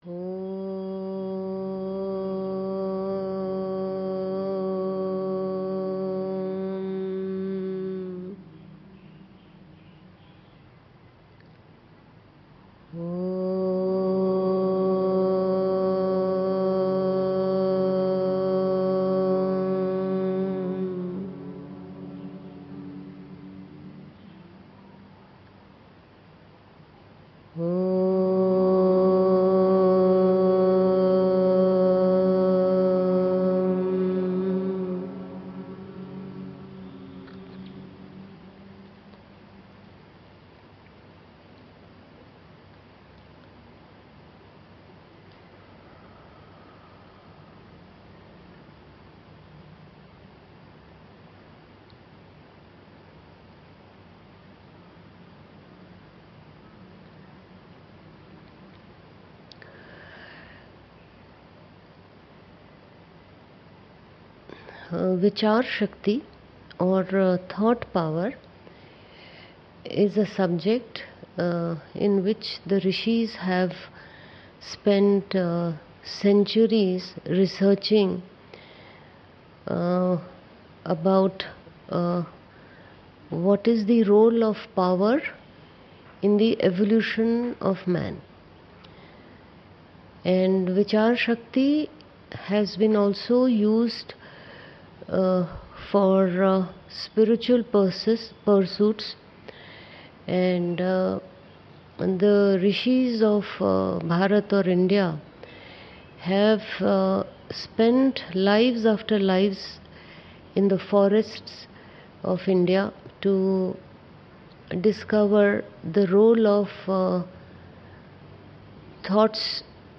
A Discourse was given on 26th Dec 2024 in Bengaluru on this write up.